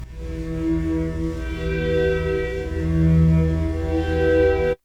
FD0611_string(1).wav